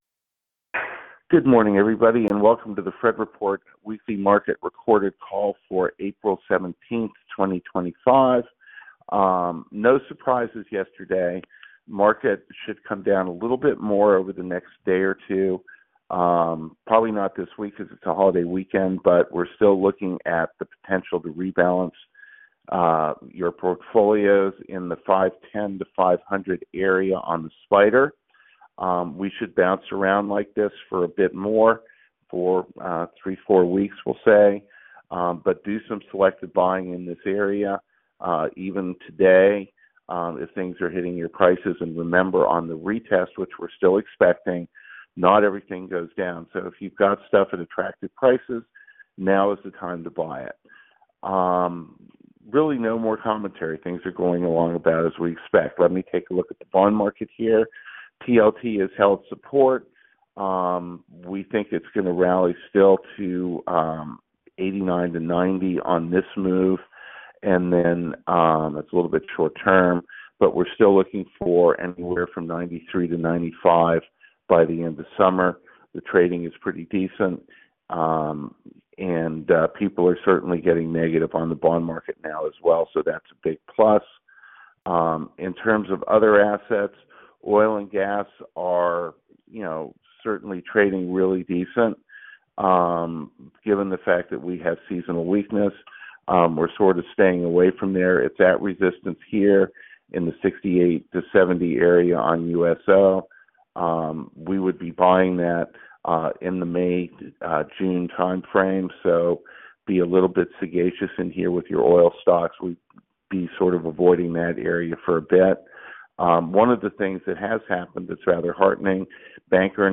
Conference Call Recording: